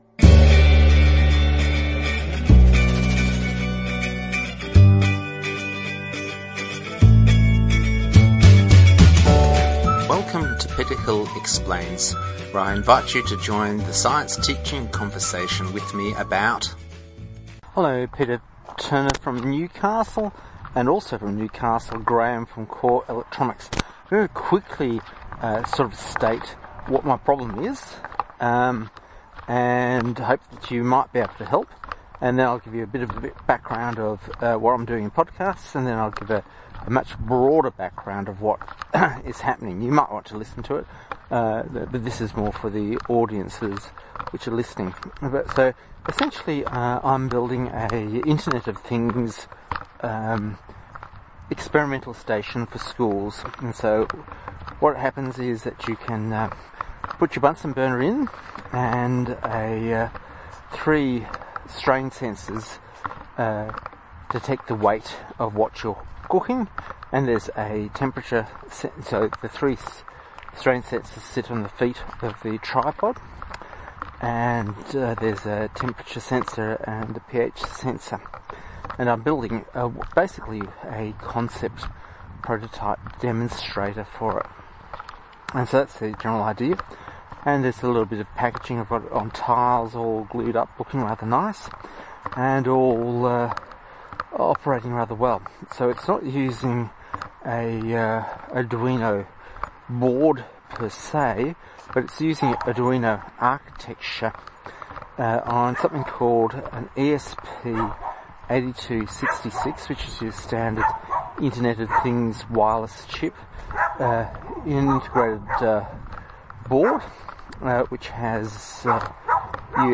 I address this podcast (forgive the walking sound as the microphone bounces on my glasses) to mates in Newcastle (110 km north of Sydney).